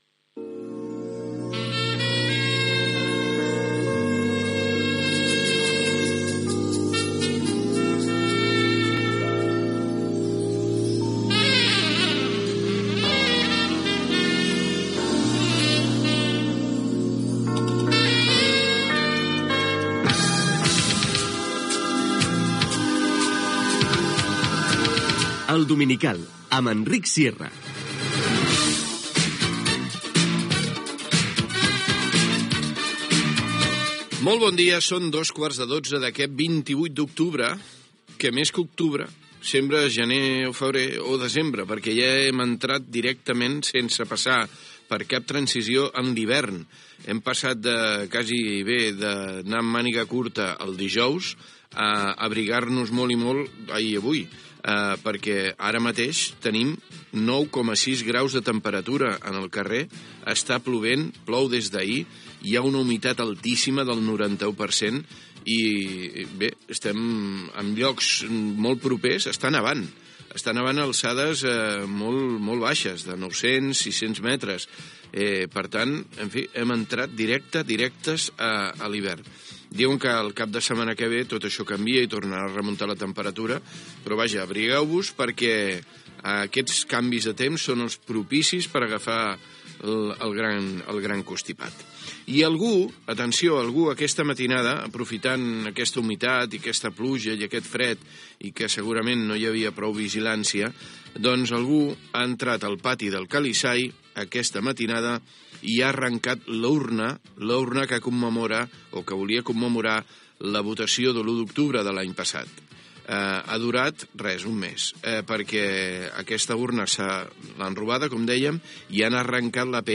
Careta del programa
comentari sobre el temps, robatori de l'urna commemorativa del Referèndum de l'1 d'octubre del 2017 a l'edifici del Calisay, comentari del mal estat de la línia del tren Mataró Barcelona i de l'autovia de peatge del Maresme, sumari de continguts Gènere radiofònic Entreteniment